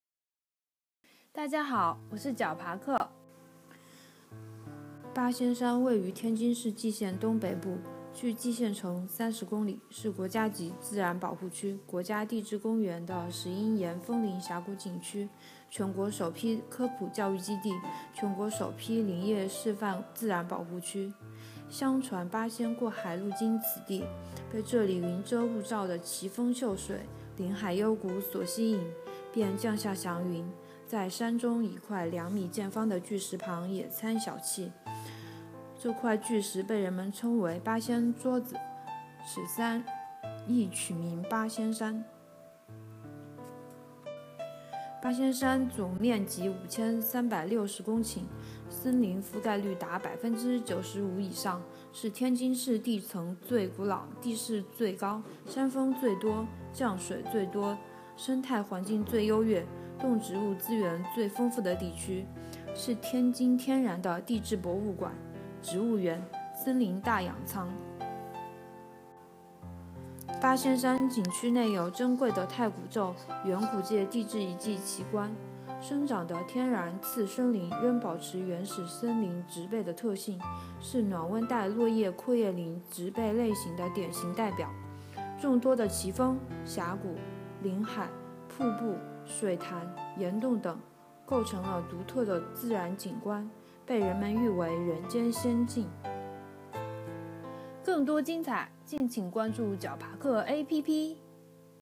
八仙山----- fin 解说词: 八仙山位于天津市蓟县东北部，距蓟县城30公里，是国家级自然保护区、国家地质公园的石英岩峰林峡谷景区、全国首批科普教育基地、全国首批林业示范自然保护区。